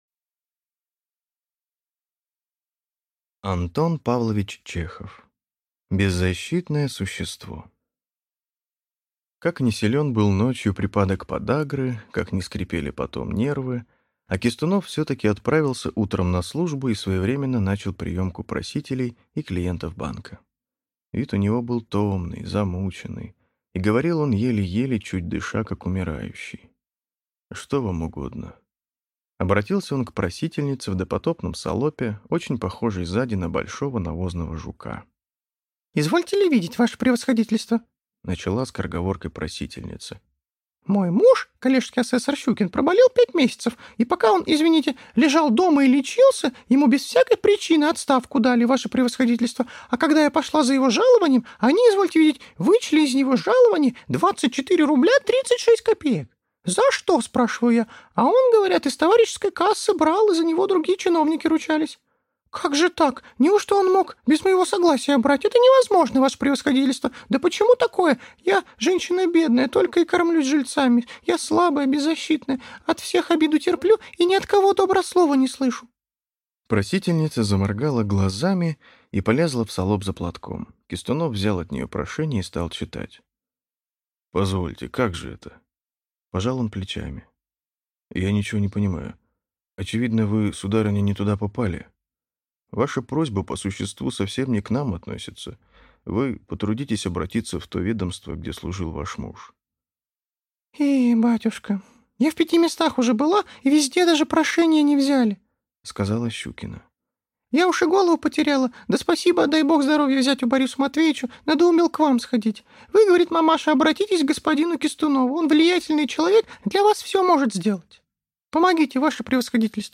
Аудиокнига Беззащитное существо | Библиотека аудиокниг